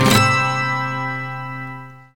GTR A.GTR0KL.wav